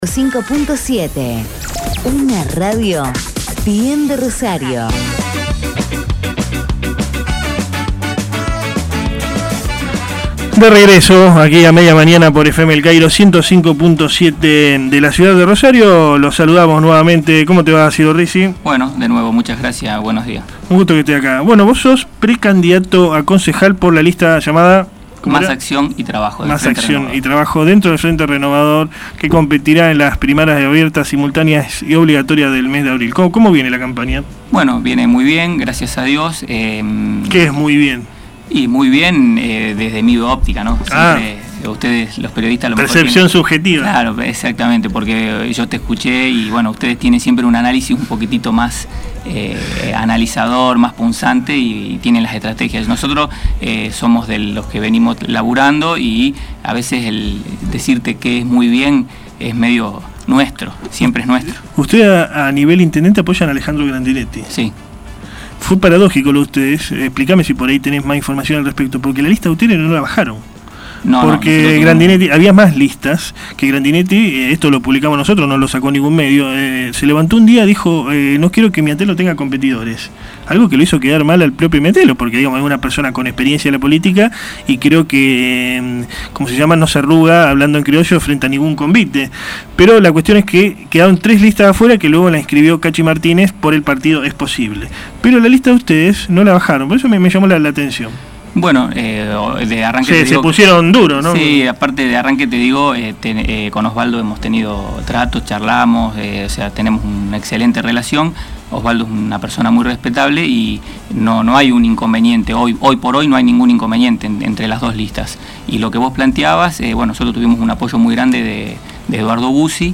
ENTREVISTA Media Mañana